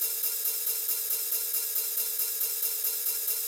16TH RIDE -R.wav